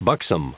Prononciation du mot buxom en anglais (fichier audio)
Prononciation du mot : buxom